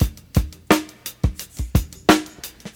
87 Bpm Breakbeat Sample B Key.wav
Free drum groove - kick tuned to the B note.
87-bpm-breakbeat-sample-b-key-pQq.ogg